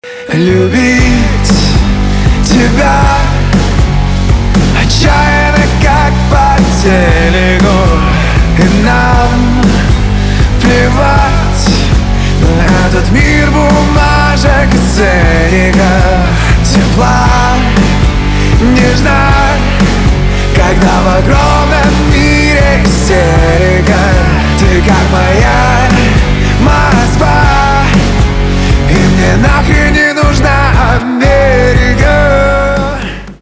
русский рок , гитара , барабаны
чувственные